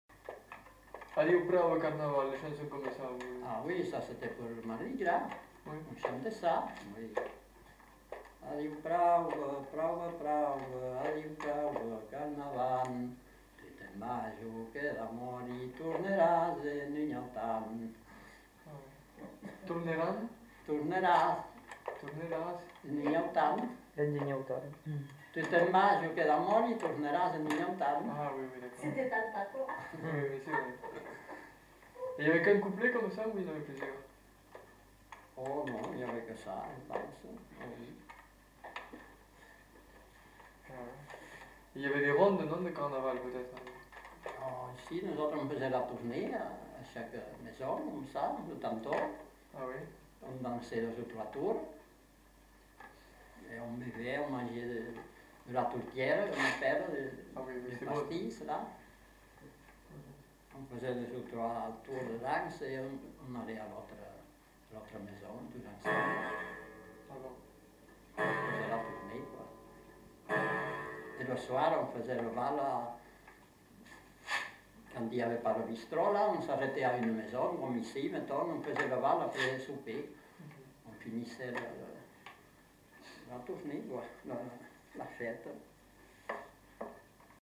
Lieu : Lencouacq
Genre : chant
Effectif : 1
Type de voix : voix d'homme
Production du son : chanté
Notes consultables : En fin de séquence, l'informateur parle de la pratique de la danse et de la musique.